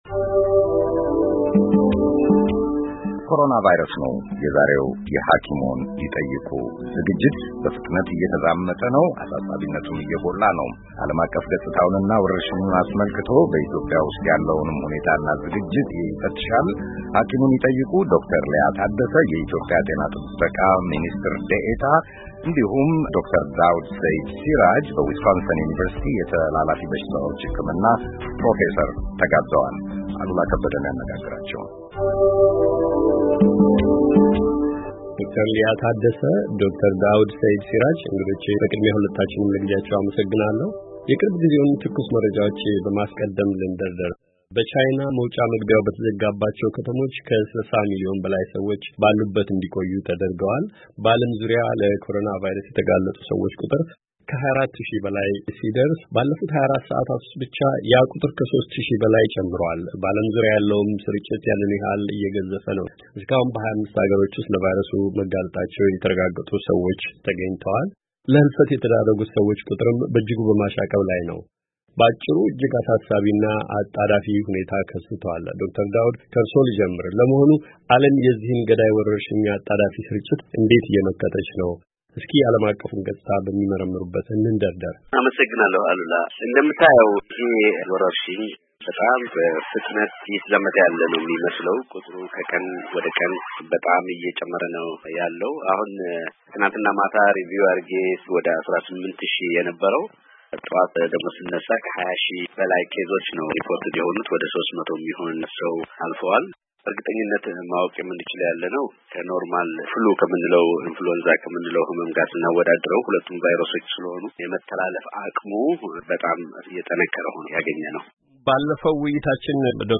ተከታዩም ውይይት የወረርሽኙን ዓለም አቀፍ ገጽታ፣ በኢትዮጵያን ያለውን ሁኔታ፣ እንዲሁም ኮረናቫይረስ በተለያየ መልኩ የደቀነውን ፈተና እና ለመከላከል የተያዘውን ዝግጅት ጭምር ይመለከታል።